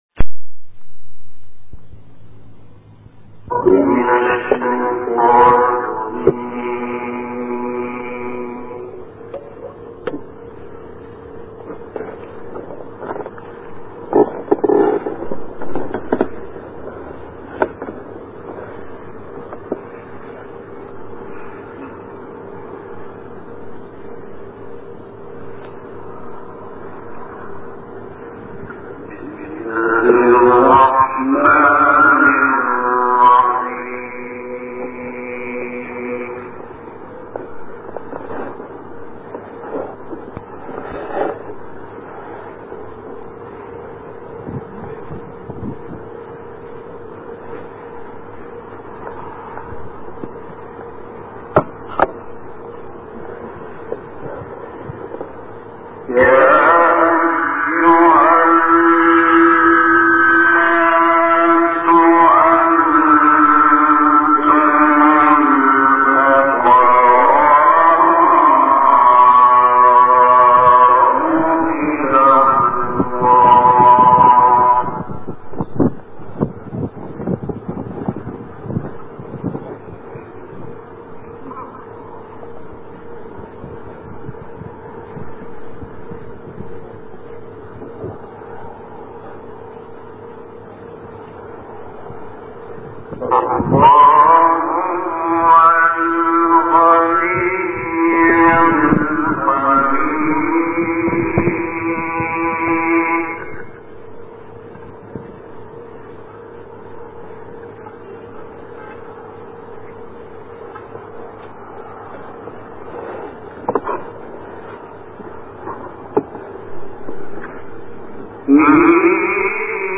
تلاوت «شحات» و «اللیثی» در محفل قرآنی
گروه شبکه اجتماعی: شحات محمد انور و محمد اللیثی در محفل قرآنی که در سال 1980 میلادی برگزار شده است به تلاوت آیاتی از کلام الله مجید پرداخته‌اند.